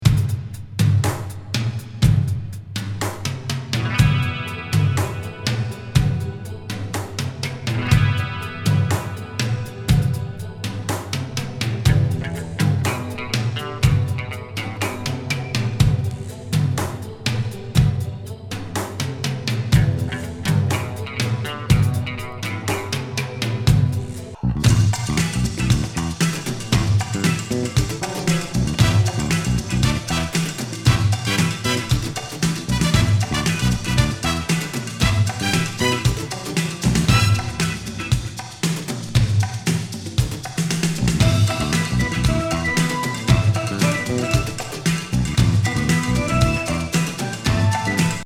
パーカッシブ・エレクトロ